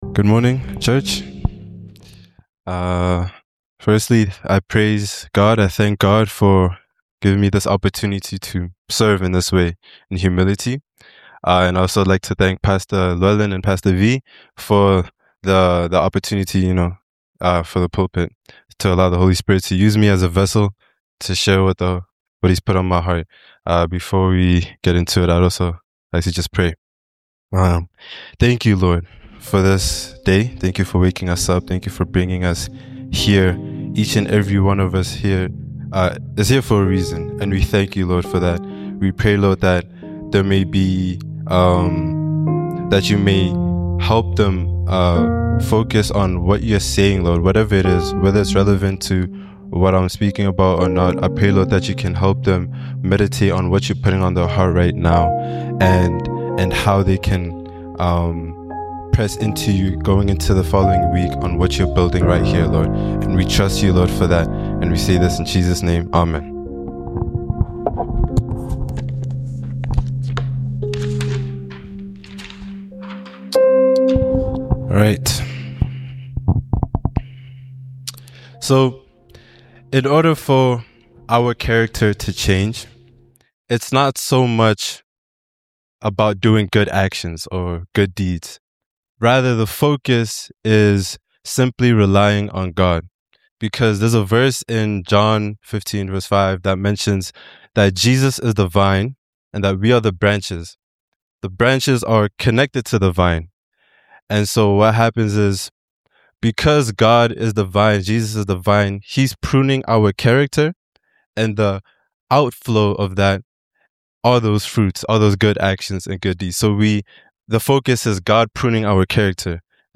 GBC Podcast to share audio sermons and talks.